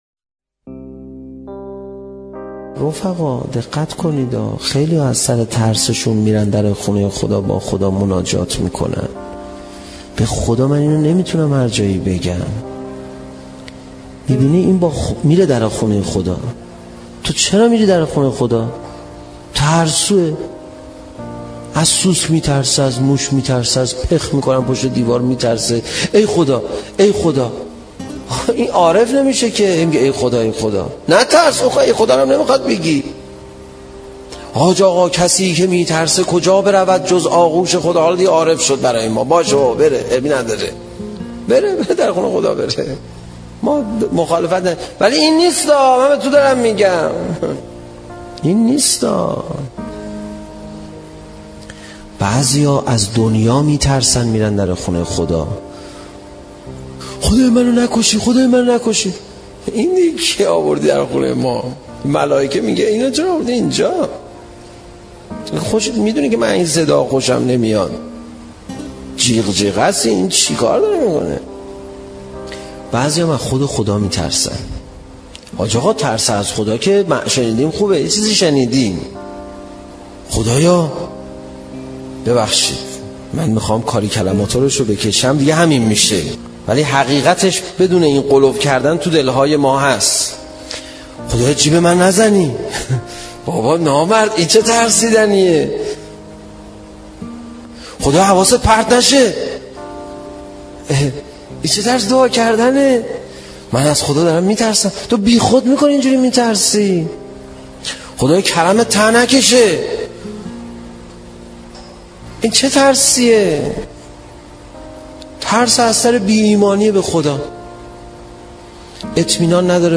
موسیقی دسته‌بندی-نشده سخنرانی